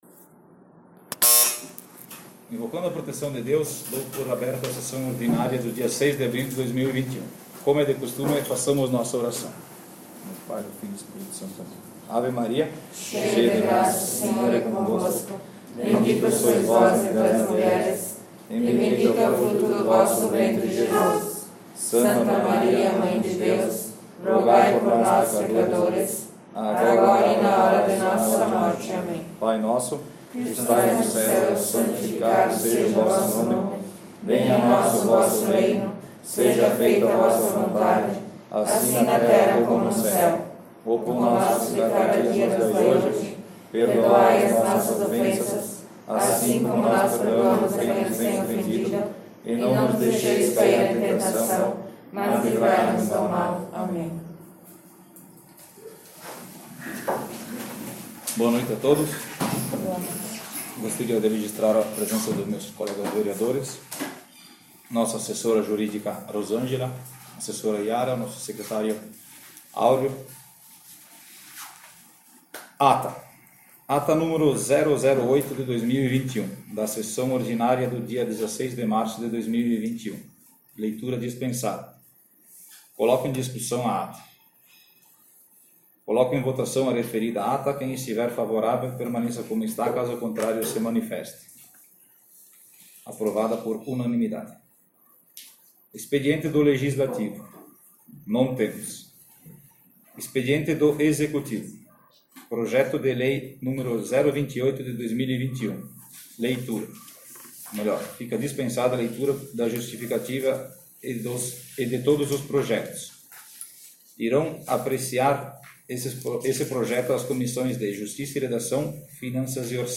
Sessão Ordinária 06-04-21